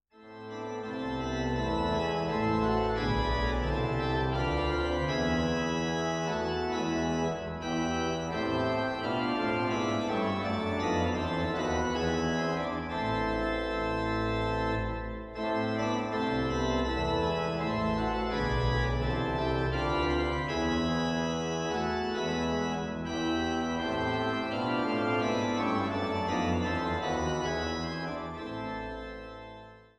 älteste Orgel im Kreis Düren